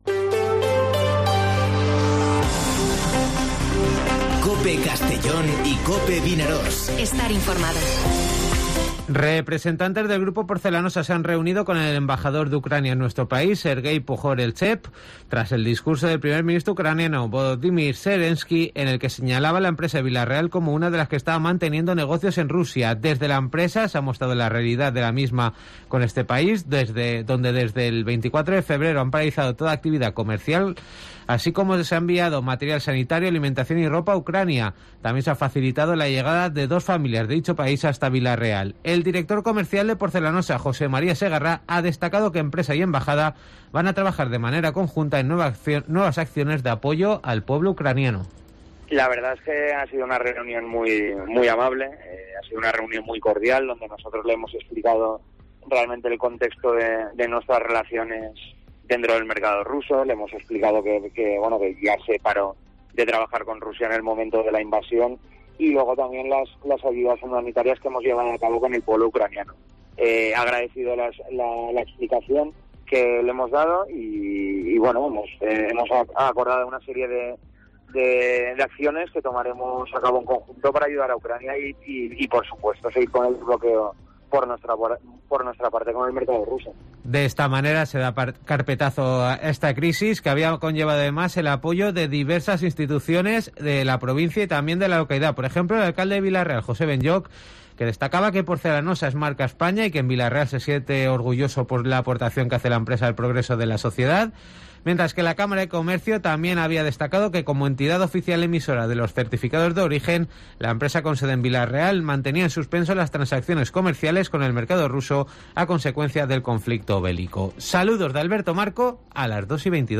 Informativo Mediodía COPE en Castellón (06/04/2022)